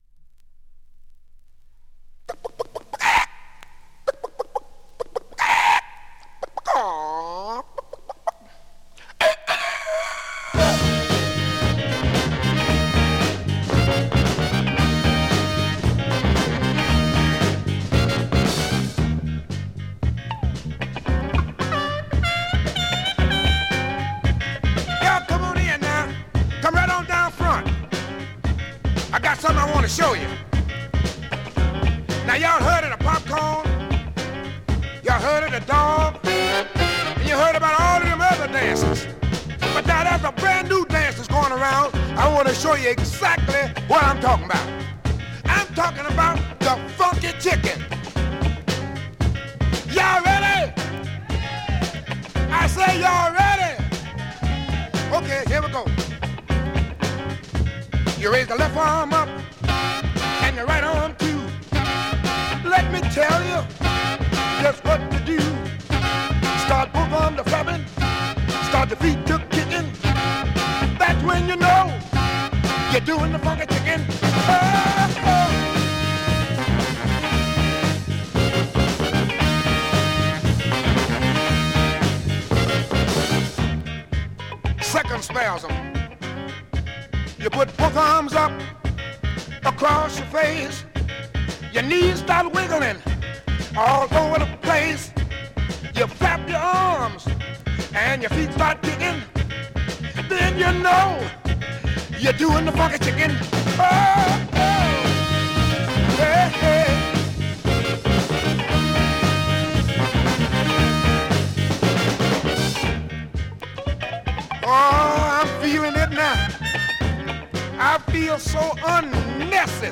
SOUL、FUNK、JAZZのオリジナルアナログ盤専門店